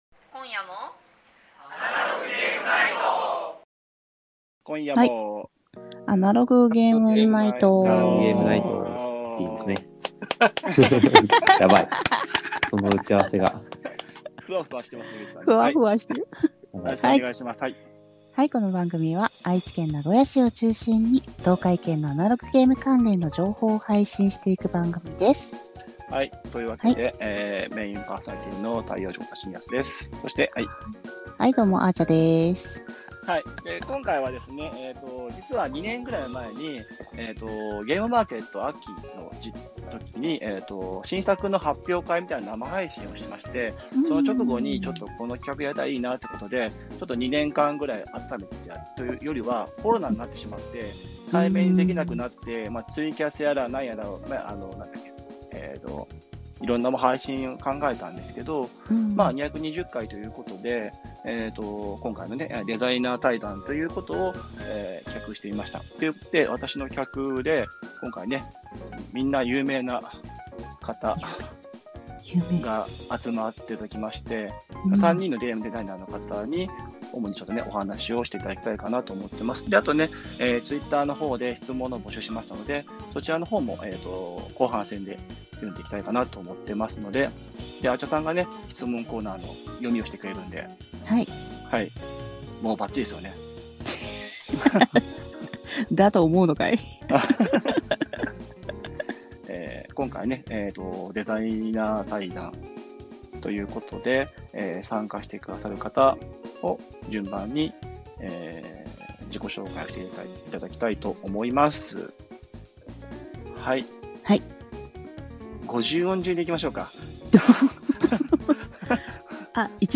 220_gamenightradio_Nagoya-GameDesiner_Zadankai.mp3